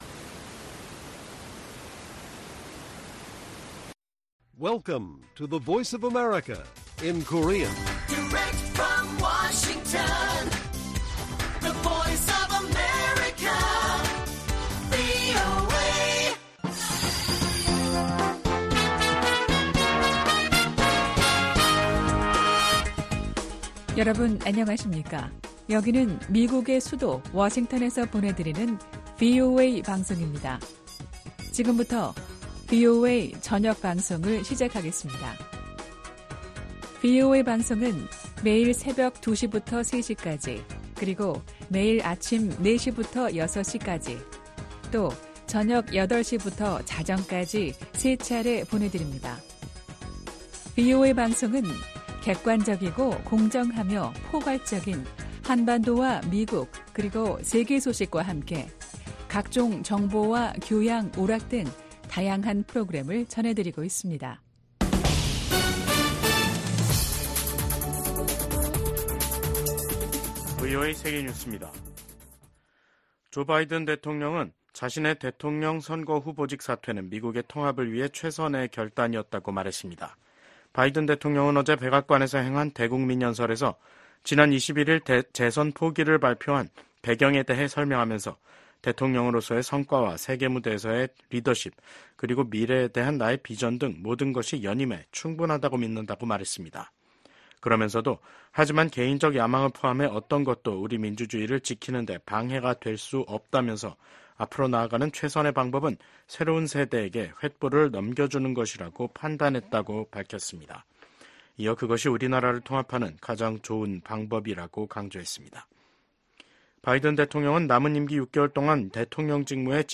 VOA 한국어 간판 뉴스 프로그램 '뉴스 투데이', 2024년 7월 25일 1부 방송입니다. 민주당 대선 후보직에서 사퇴한 조 바이든 미국 대통령이 대국민 연설을 통해 민주주의의 수호화 통합을 강조했습니다. 미국 정부가 북한의 미사일 관련 기술 개발을 지원한 중국 기업과 중국인에 신규 제재를 부과했습니다.